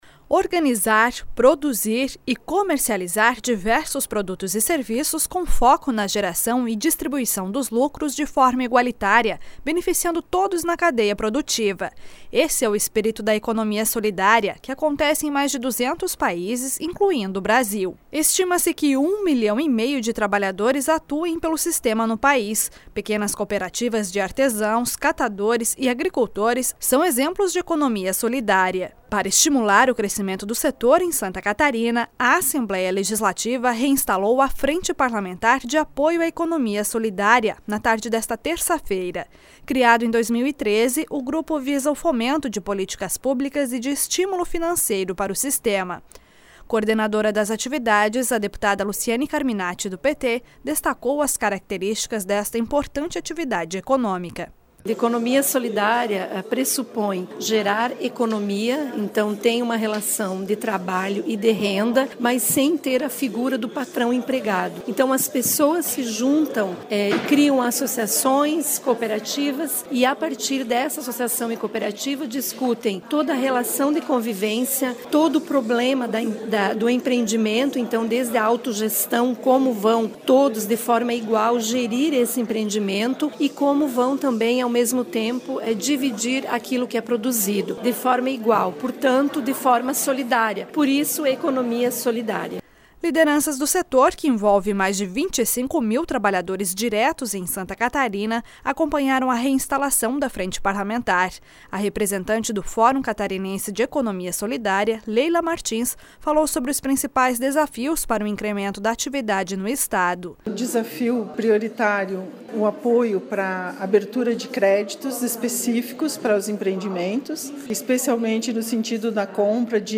Entrevistas com:
- deputada Luciane Carminatti (PT), coordenadora da Frente Parlamentar de Apoio à Economia Solidária